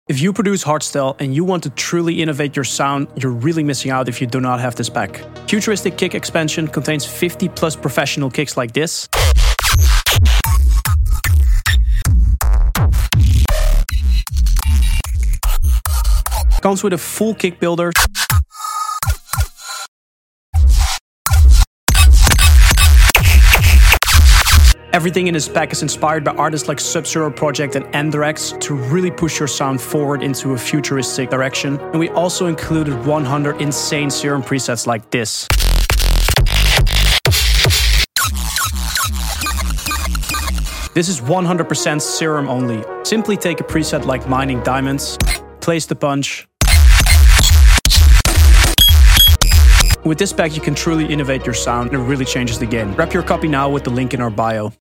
Some of the craziest Sound-Design